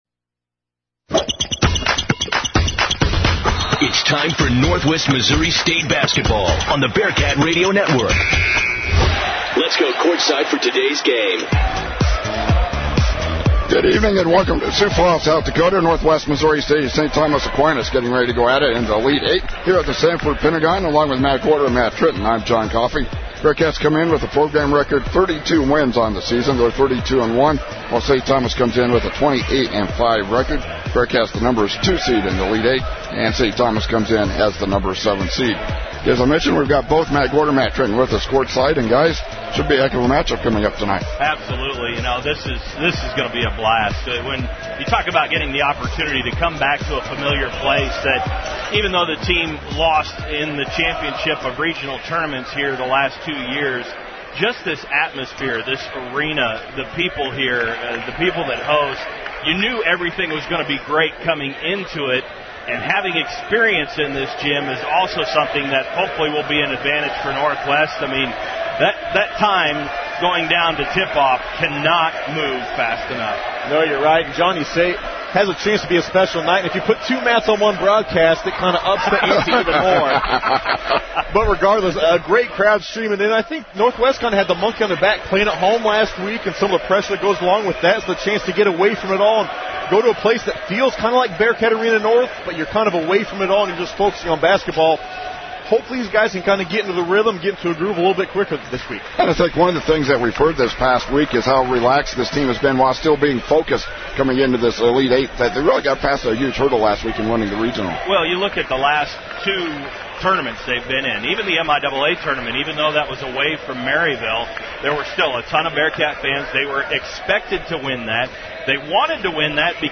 March 22, 2017Game | Basketball